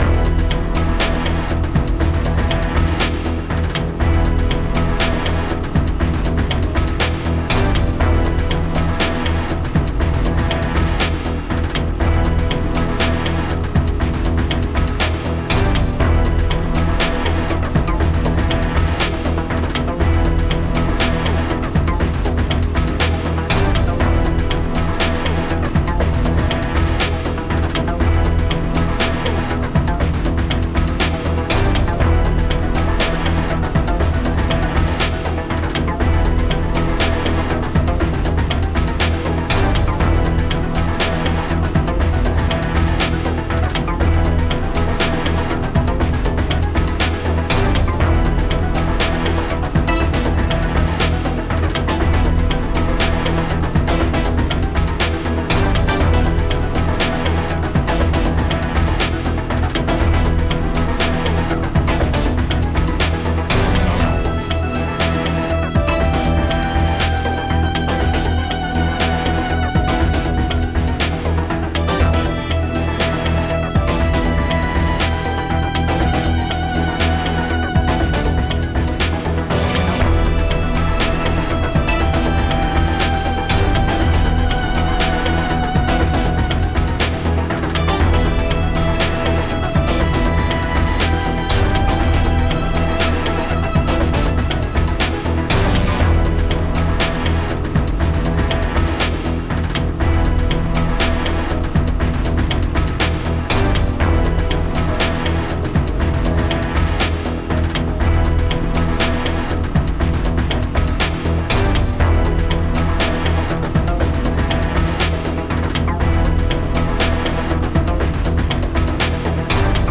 Epic alien rock